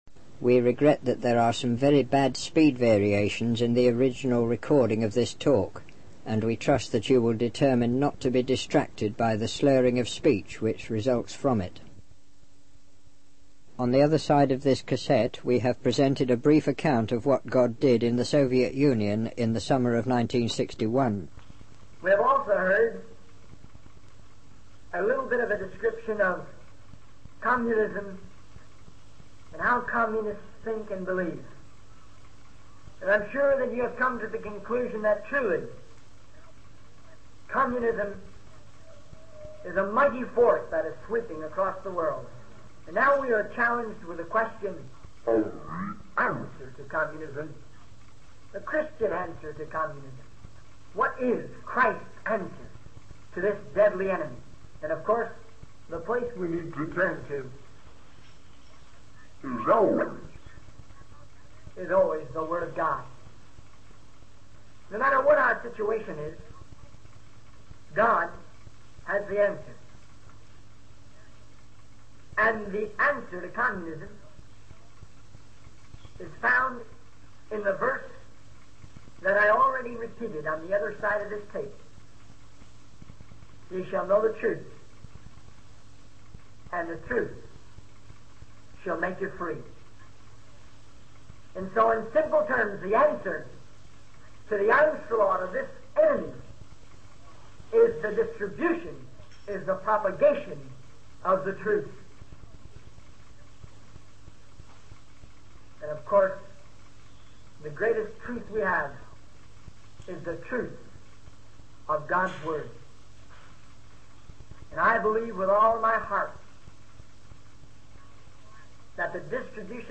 The video is a sermon about the spread of Communism in the Soviet Union in the summer of 1961. The speaker emphasizes the need for Christians to understand the truth and spread the gospel in response to this threat.